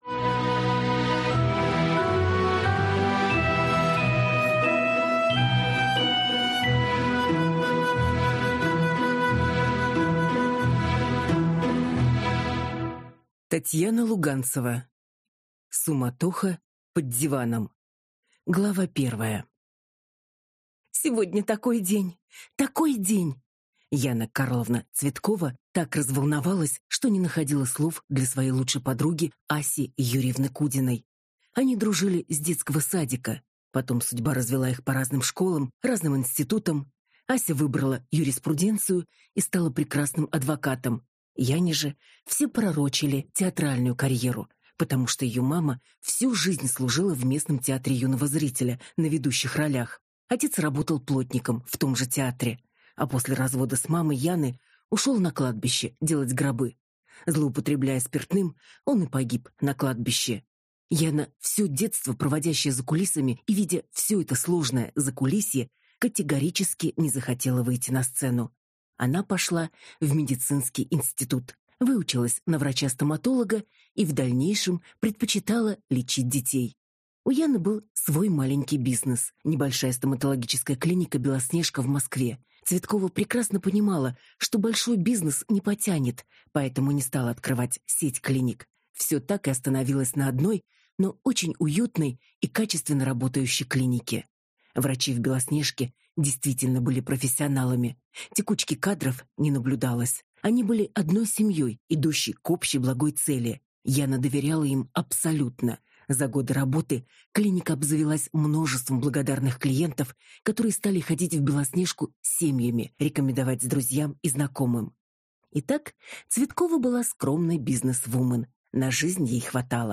Аудиокнига Суматоха под диваном | Библиотека аудиокниг